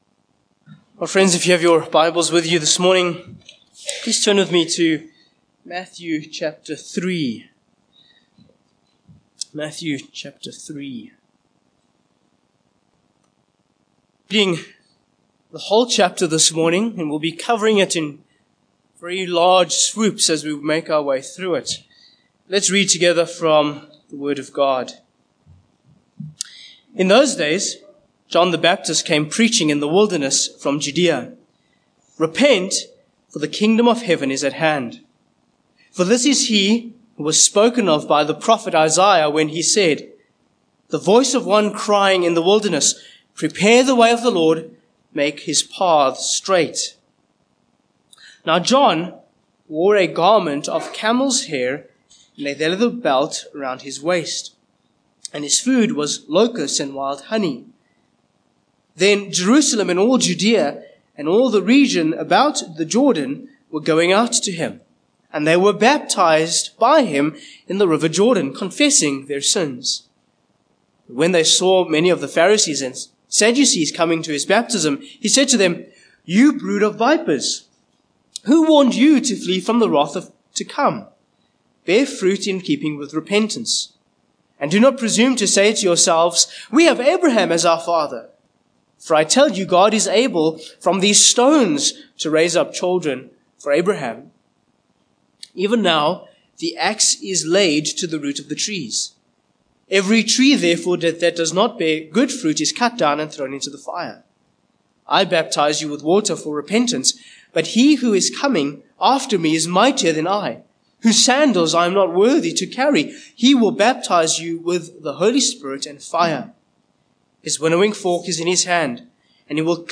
Matthew 3:1-17 Service Type: Morning Passage